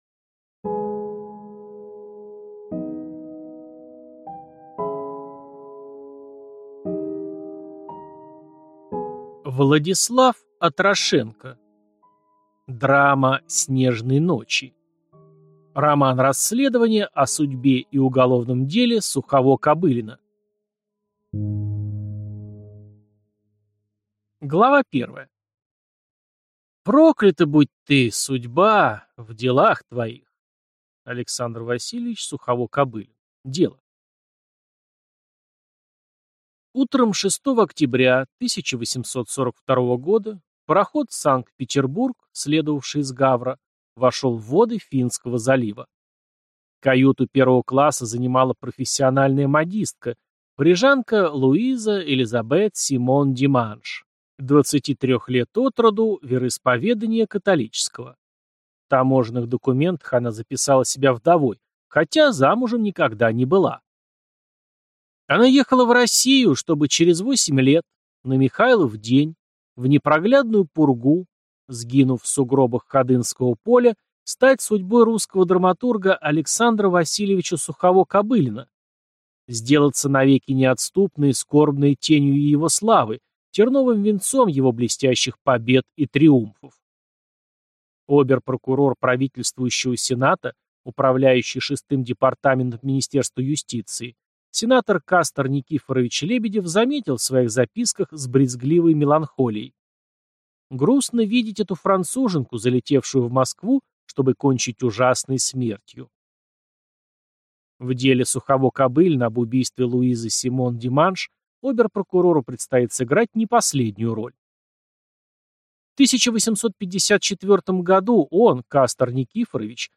Аудиокнига Драма снежной ночи. Роман-расследование о судьбе и уголовном деле Сухово-Кобылина | Библиотека аудиокниг